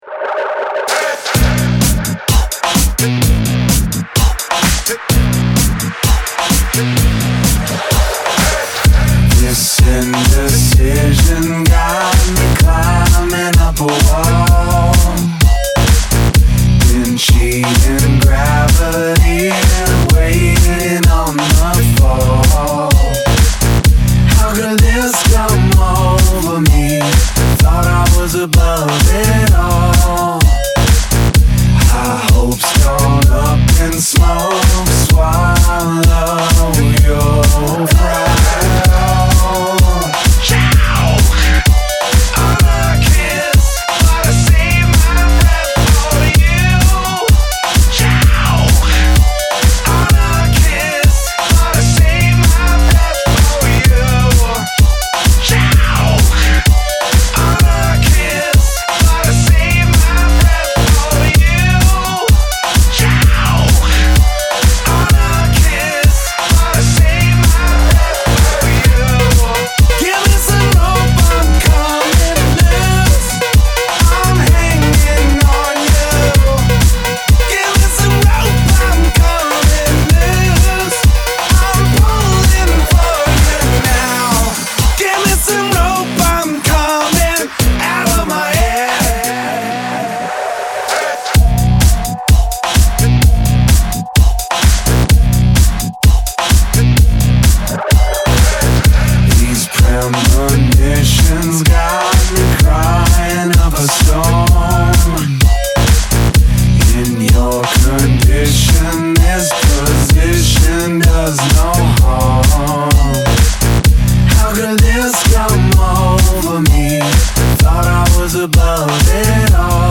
we get a remix from the Electronic pride of Canuckistan